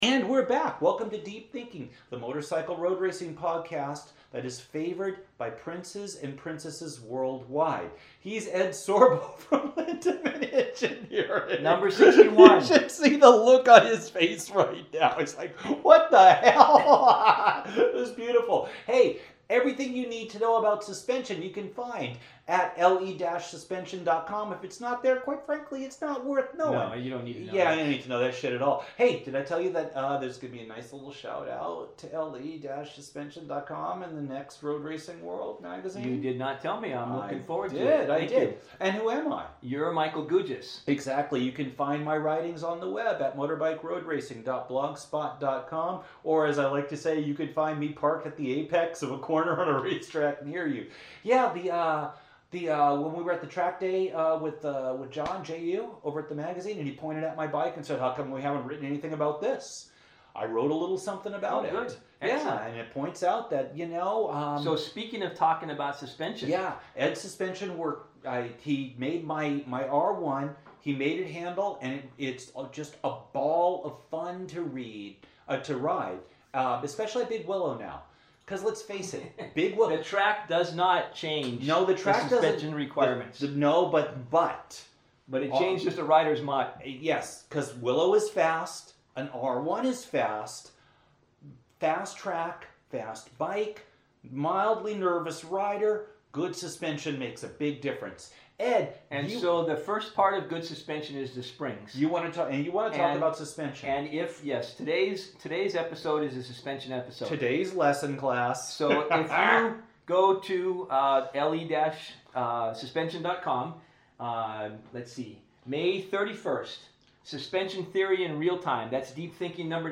In Episode 61 of Deep Thinking, the best motorcycle road racing podcast recorded in the greater Redlands metropolitan area